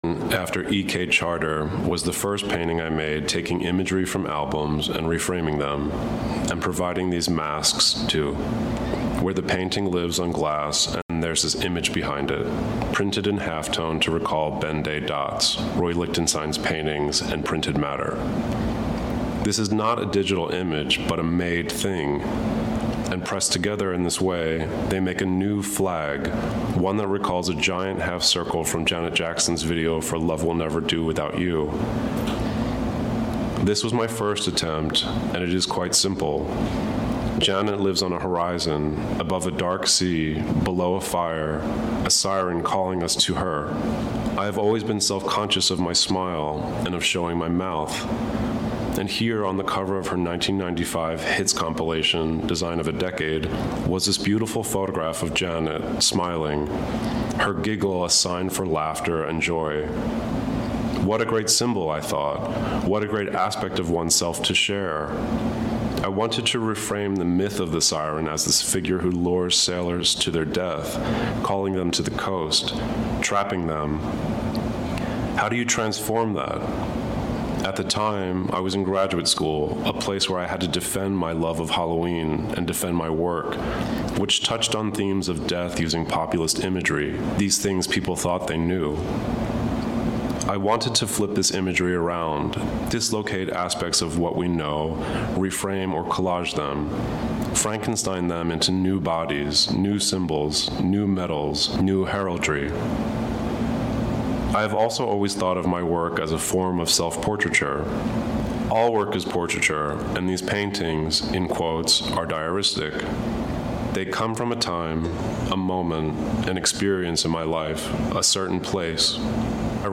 Clicking on it will let you listen to Da Corte himself as he comments on a work or on his own technique.
Be sure to listen to Da Corte's extended comments.